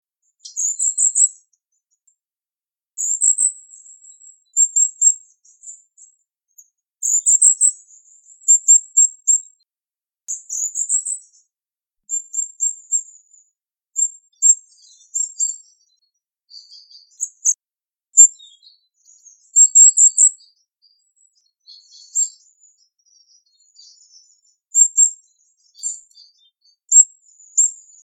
Regulus regulus - Goldcrest - Regolo
- IDENTIFICATION AND BEHAVIOUR: One female goldcrest is feeding in small bushes at the border of an oak wood.
E 11° 16' - ALTITUDE: +130 m. - VOCALIZATION TYPE: contact calls.
- COMMENT: The spectrograms show 3 call types, whose remarkable difference cannot be easily appreciated by ear.
Recording background: Blackbird calls and Robin song (far).